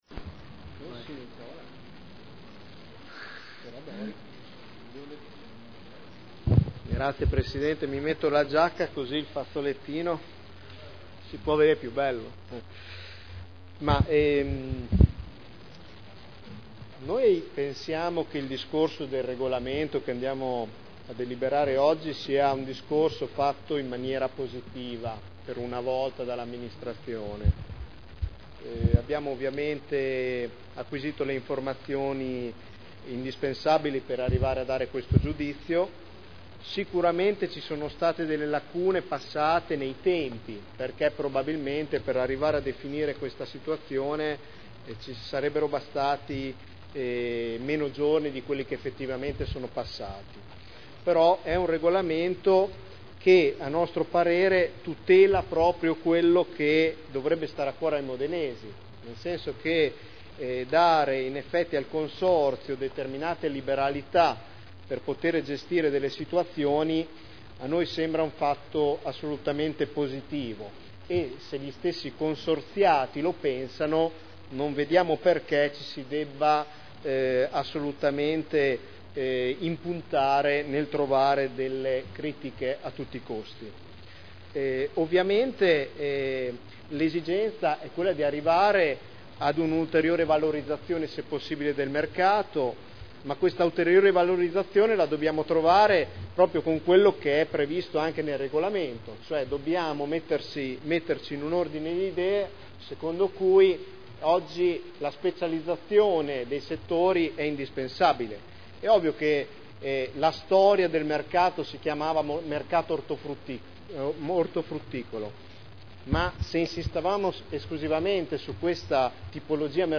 Nicola Rossi — Sito Audio Consiglio Comunale